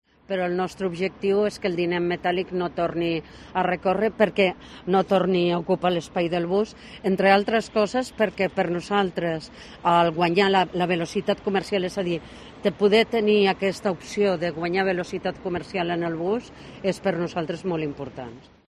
Rosa Alarcón, presidenta de TMB, explica las ventajas del billete sencillo electrónico